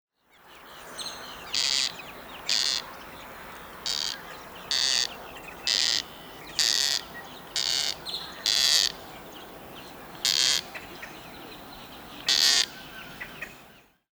На этой странице собраны разнообразные звуки коршуна – от резких криков до переливчатых трелей.
Коршун слизнеед издает такой звук